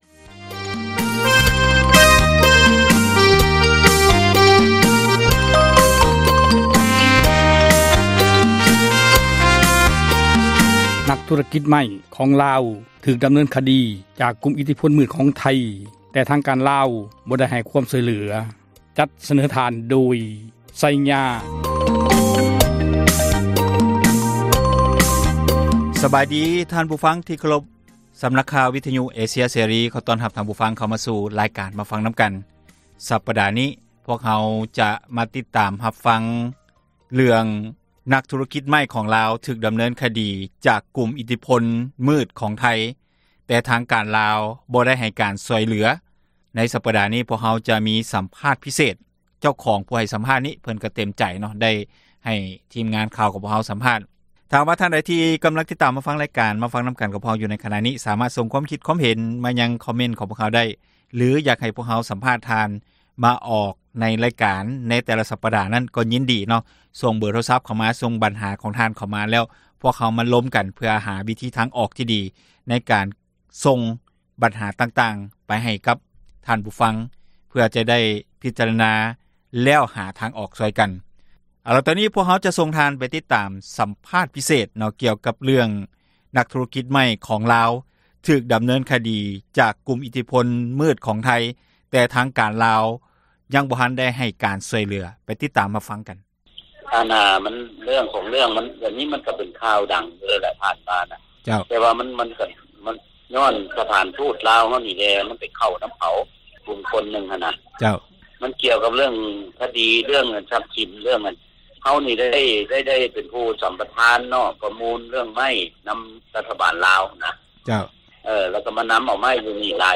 "ມາຟັງນຳກັນ" ແມ່ນຣາຍການສົນທະນາ ບັນຫາສັງຄົມ ທີ່ຕ້ອງການ ພາກສ່ວນກ່ຽວຂ້ອງ ເອົາໃຈໃສ່ແກ້ໄຂ, ອອກອາກາດ ທຸກໆວັນອາທິດ ເວລາ 6:00 ແລງ ແລະ ເຊົ້າວັນຈັນ ເວລາ 7:00